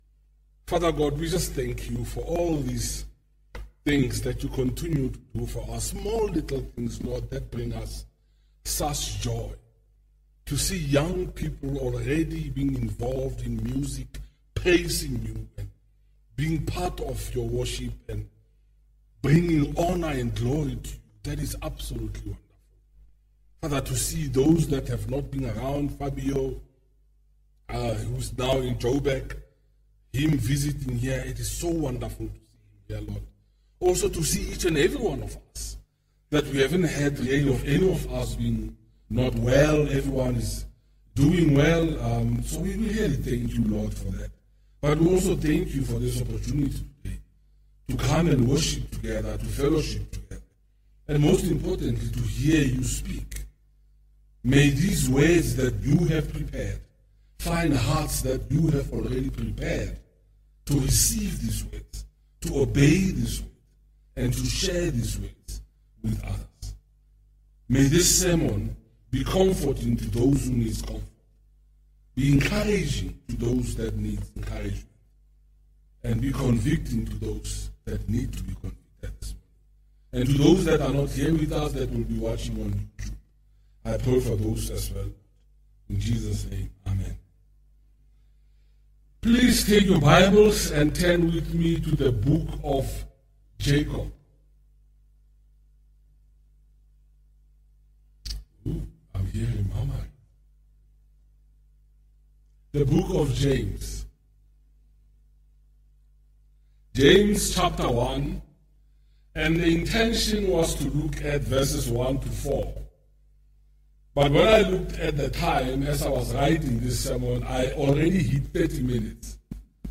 Passage: 1 Thessalonians 4: 1-3 Service Type: Morning Service